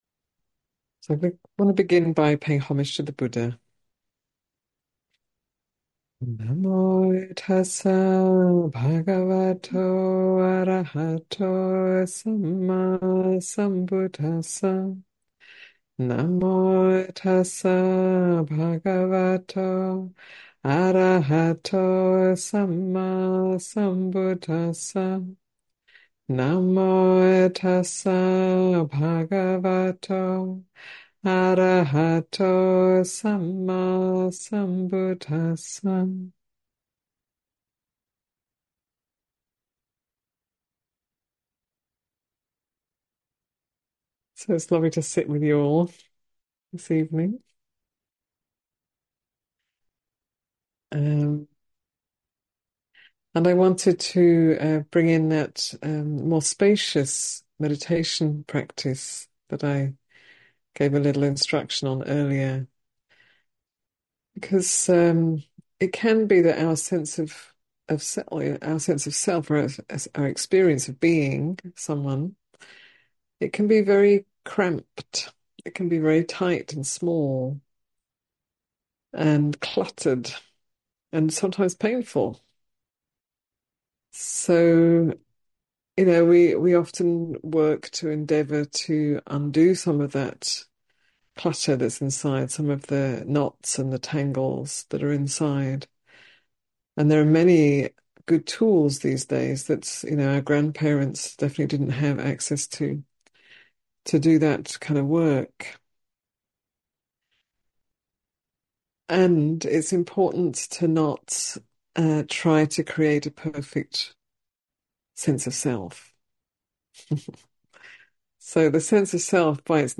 Marin Sangha Talks